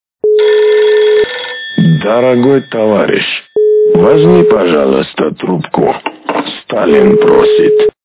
» Звуки » Смешные » Дорогой, возьми, пожалуйста, трубку. - Сталин просит
При прослушивании Дорогой, возьми, пожалуйста, трубку. - Сталин просит качество понижено и присутствуют гудки.